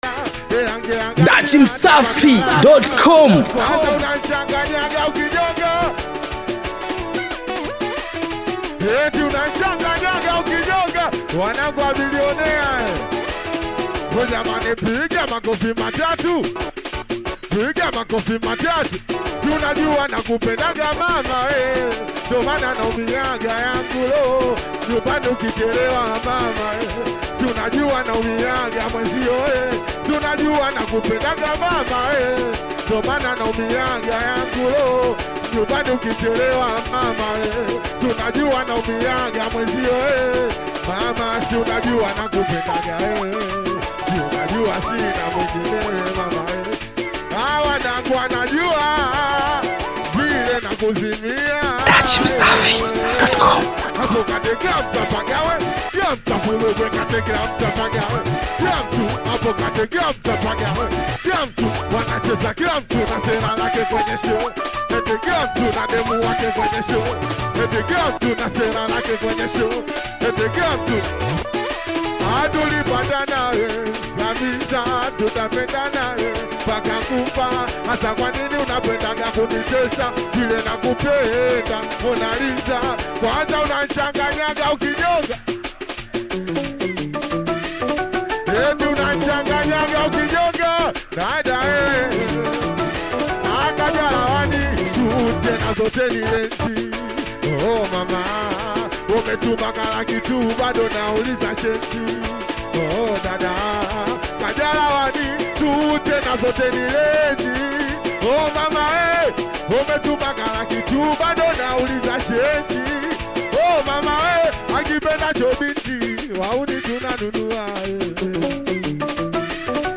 Mp3 Download SHOW LIVE MPYA 2025 NYIMBO KALI MOROGORO